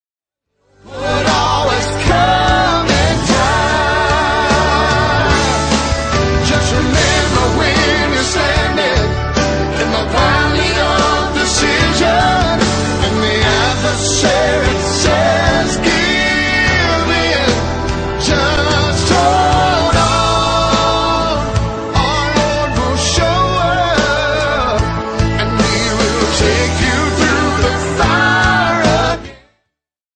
Category Country